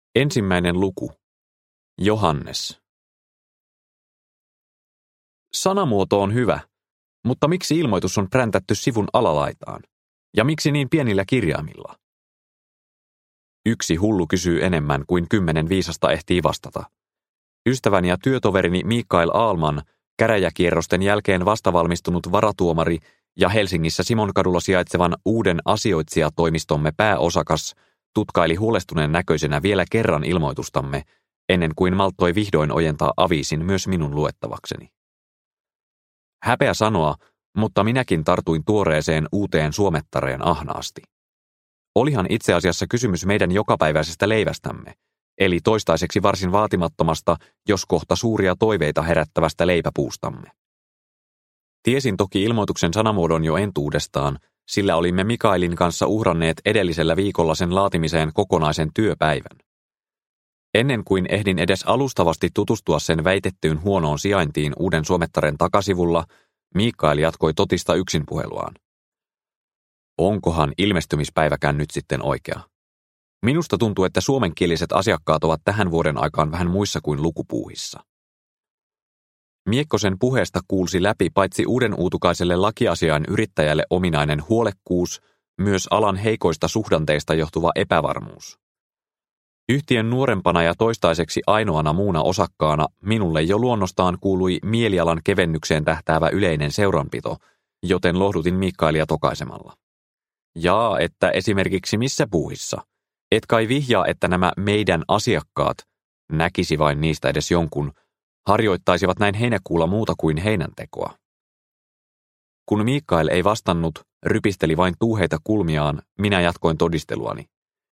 Murha Monrepoossa – Ljudbok – Laddas ner